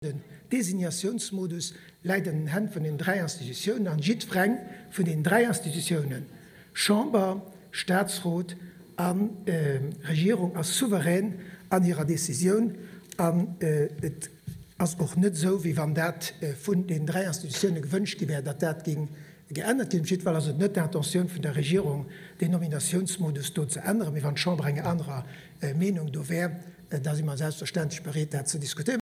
Fir de Premier Luc Frieden gëtt et kee Besoin eppes um System vun den Nominatioune fir de Staatsrot ze änneren. Dat äntwert hien an der Chamber op eng Fro vum LSAP-Deputéierten Dan Biancalana.